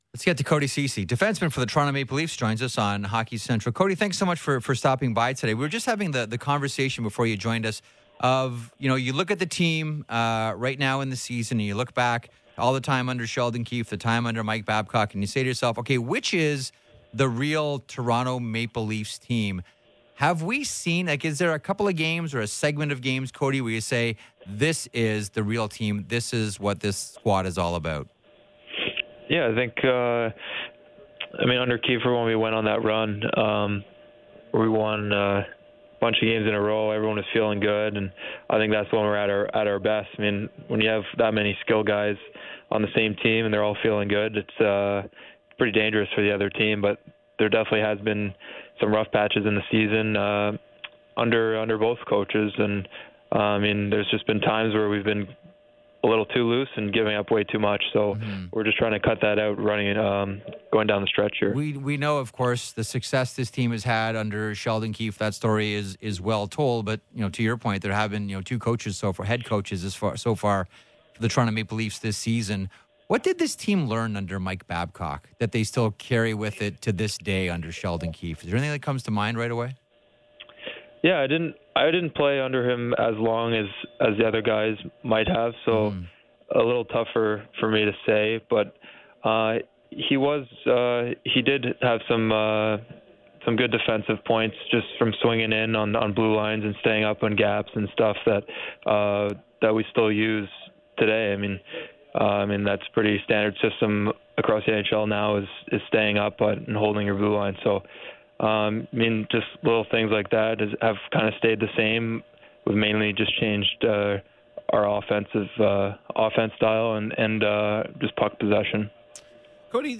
Listen to Ceci’s full interview with Sportsnet 590’s Hockey Central team via the audio player embedded within this post.